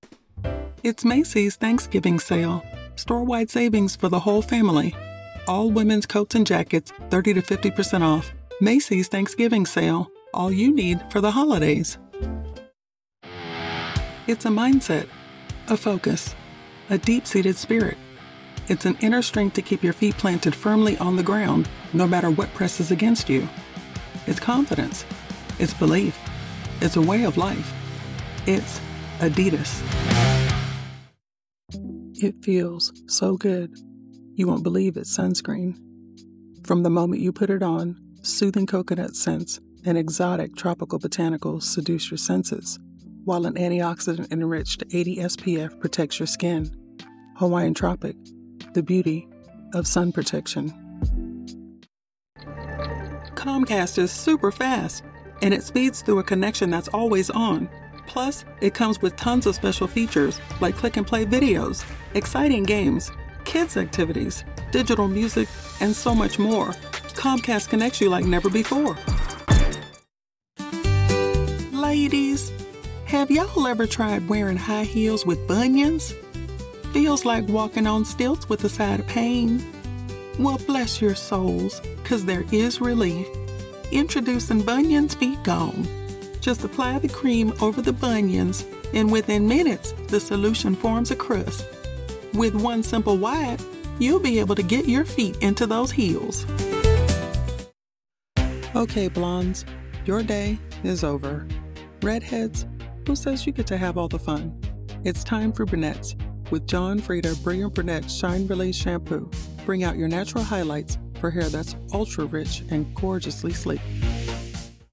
Browse professional voiceover demos.
0925Commercial_Demos.mp3